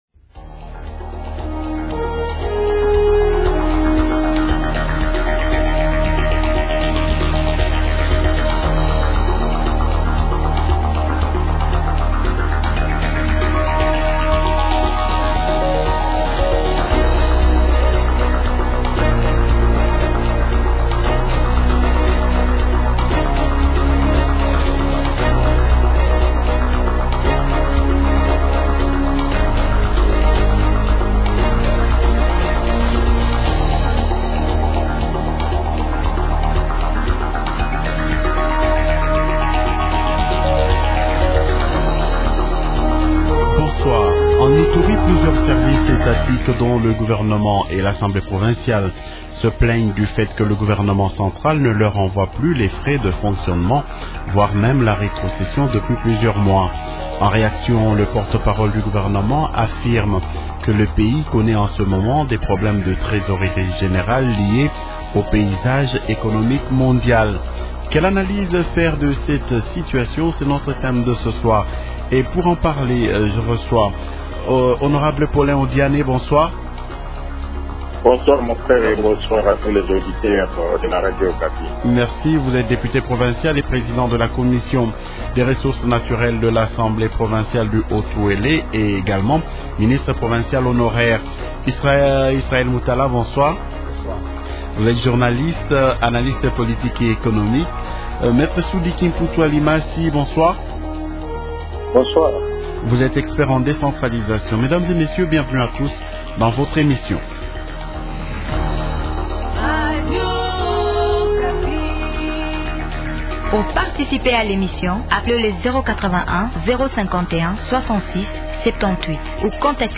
Invités : -Paulin Odiane, Député provincial et président de la Commission des ressources naturelles de l’Assemblée provinciale du Haut-Uele.